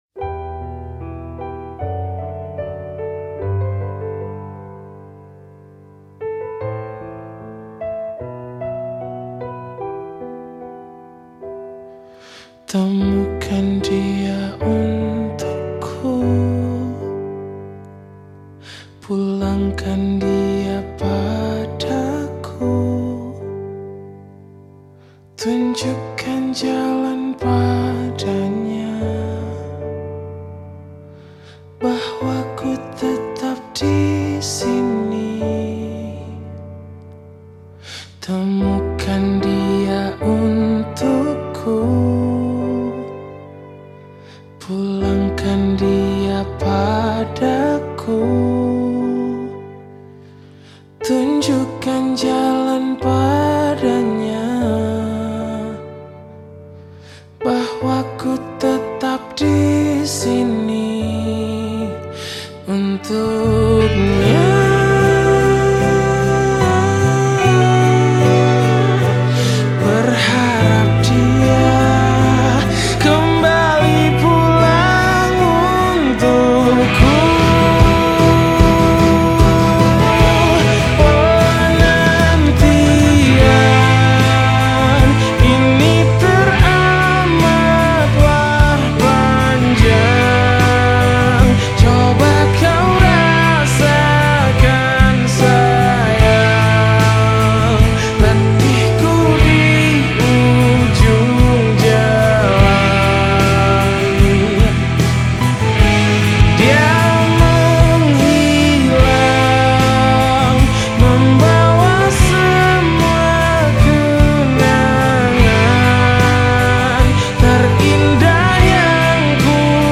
Genre Musik                      : Pop Melayu, Pop